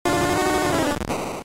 Cri de Goupix K.O. dans Pokémon Diamant et Perle.